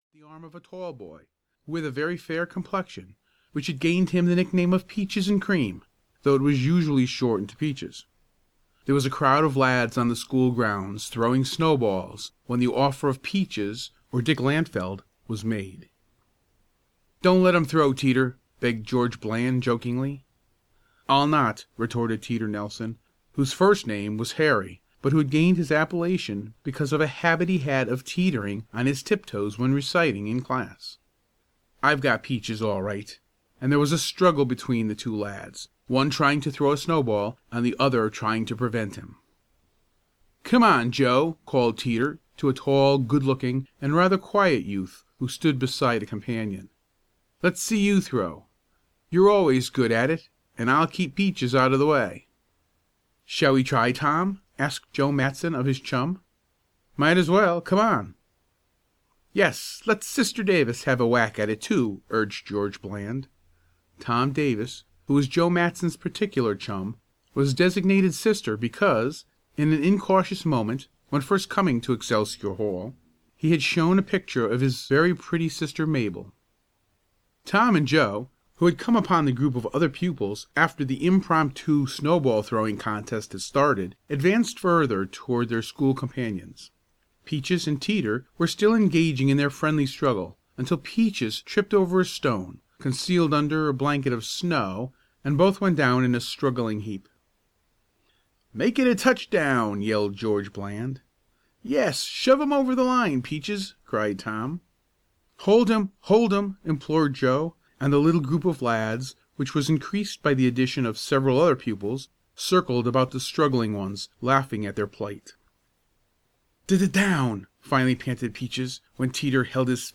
Audio knihaBaseball Joe on the School Nine (EN)
Ukázka z knihy